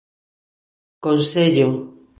Pronunciado como (IPA)
/konˈseʎo̝/